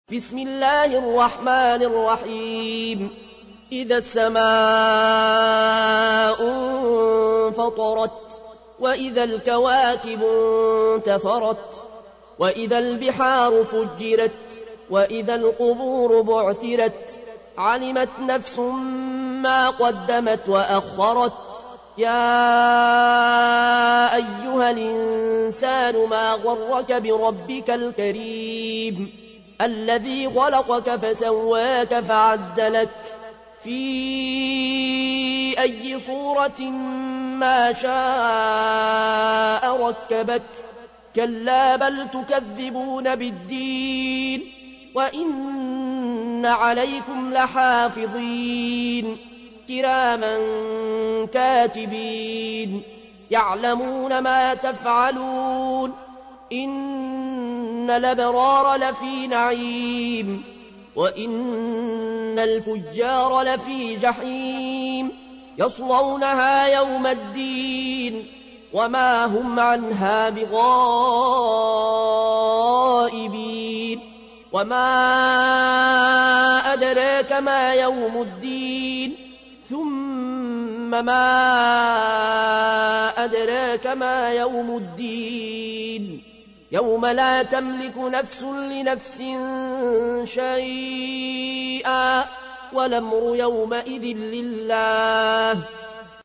تحميل : 82. سورة الانفطار / القارئ العيون الكوشي / القرآن الكريم / موقع يا حسين